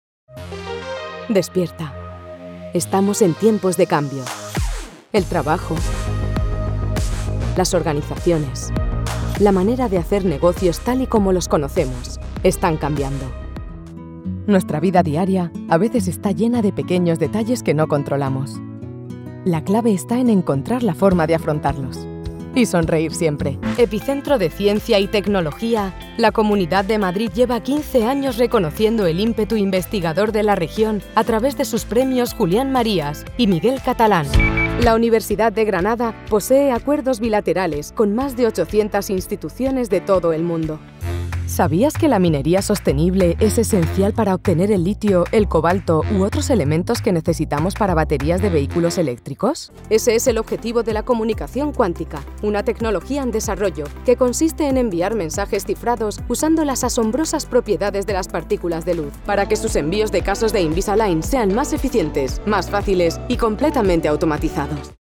Corporate Videos
European Spanish female voice over talent at your disposal!
I have a current, relatable, on trend voice, that is bright, clear, informative and engaging.
Iberian Spanish accent. 🙂